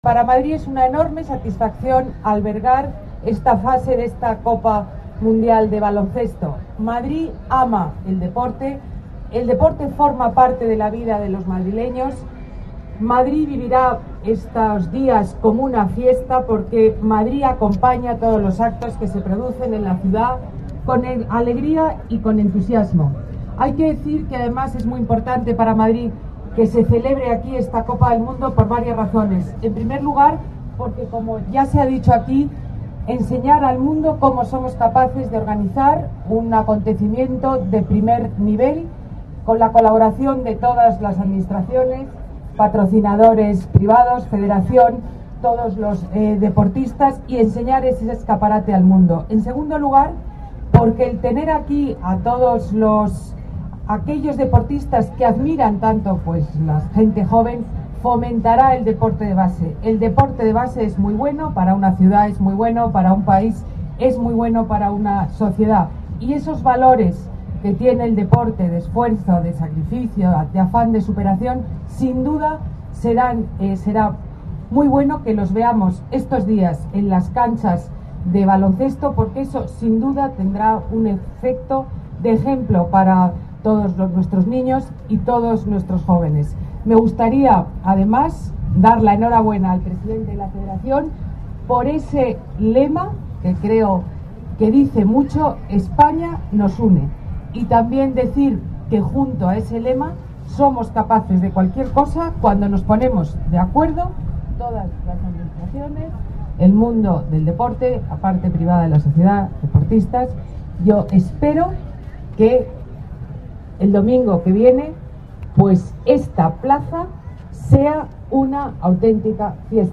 Nueva ventana:Declaraciones de la alcaldesa Ana Botella: Baloncesto Colón